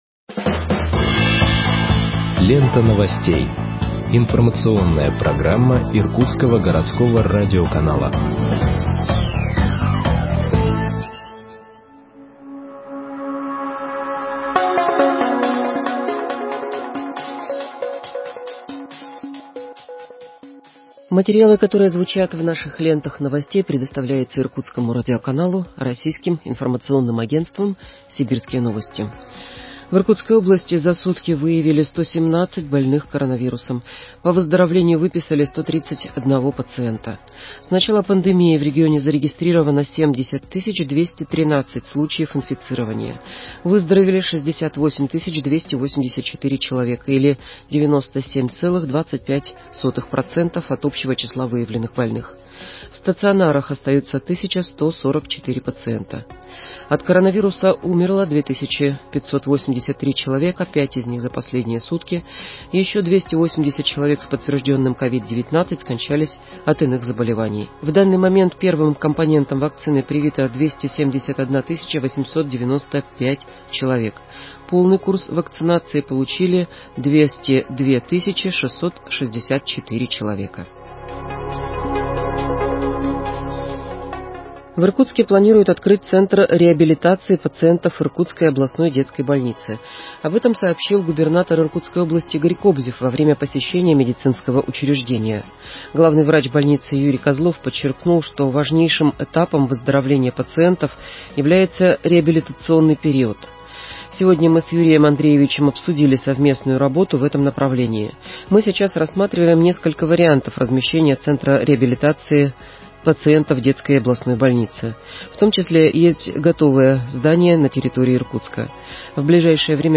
Выпуск новостей в подкастах газеты Иркутск от 02.06.2021 № 2